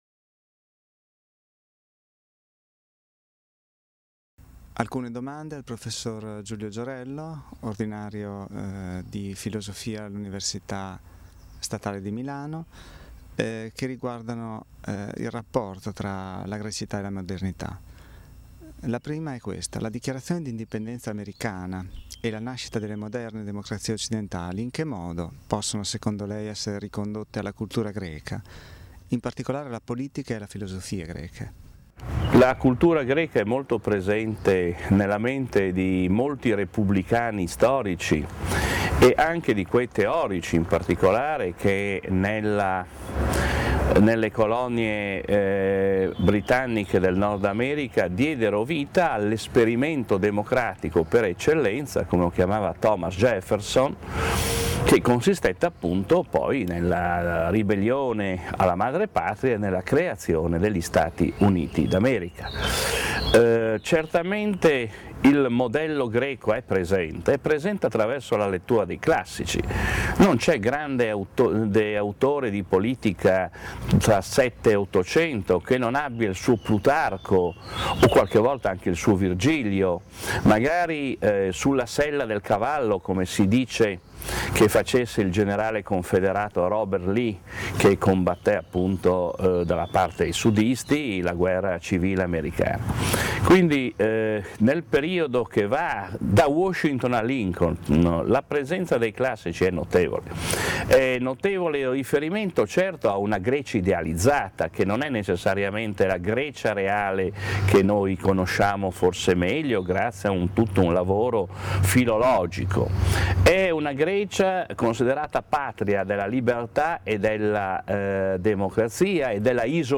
Intervista a Giulio Giorello alle Vacances de l'Esprit 2008